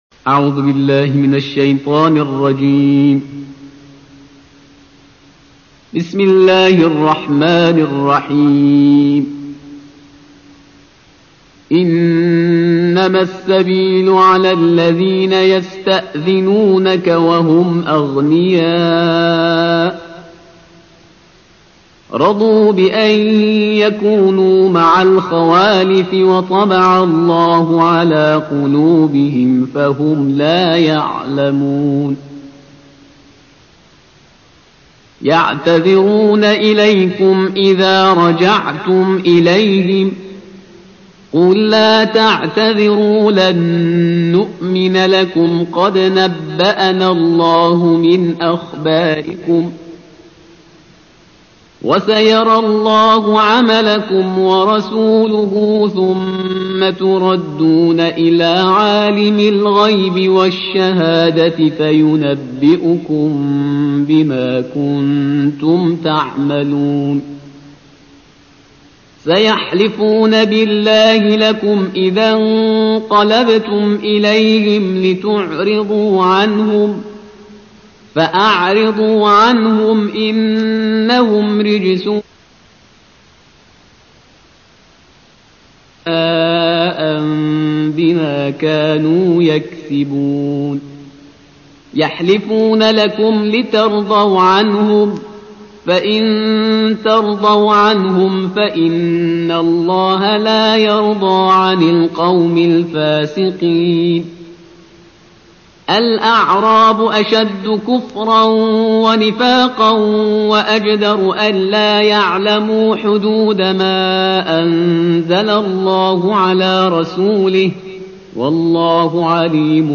تلاوت ترتیل جزء یازدهم کلام وحی با صدای استاد